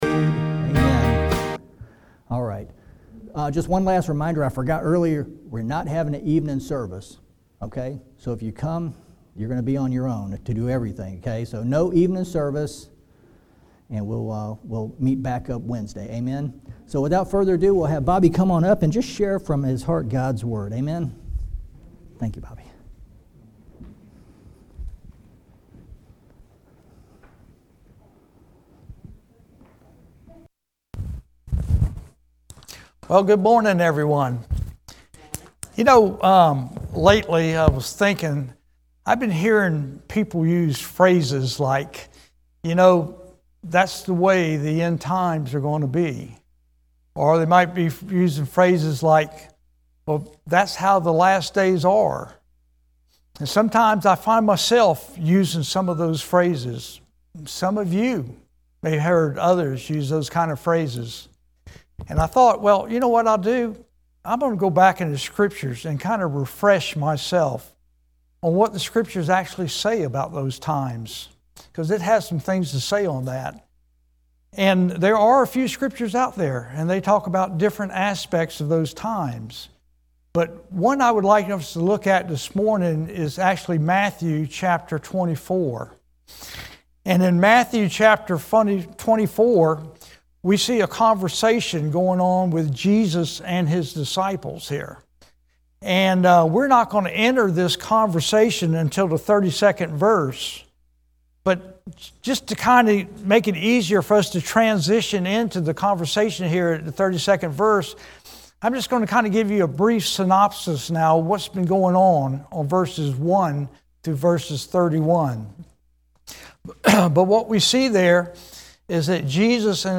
Coastal Shores Baptist Church